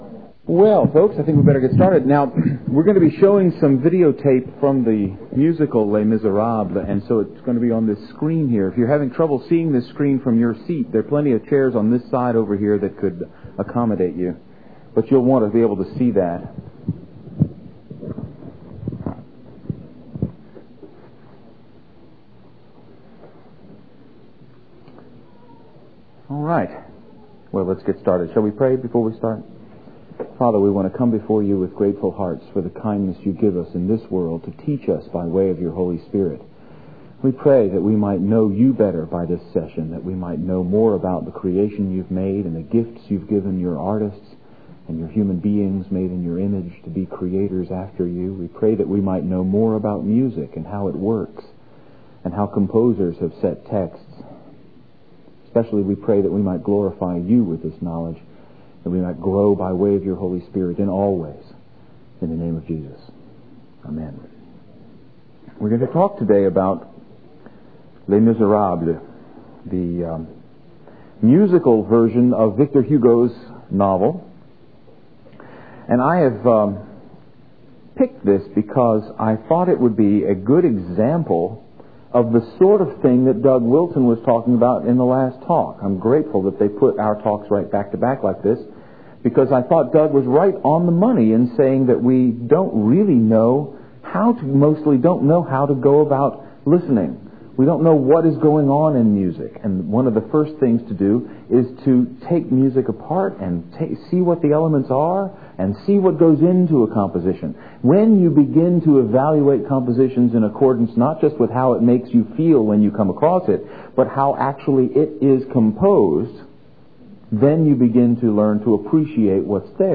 2001 Workshop Talk | 1:06:23 | All Grade Levels, Art & Music
The Association of Classical & Christian Schools presents Repairing the Ruins, the ACCS annual conference, copyright ACCS.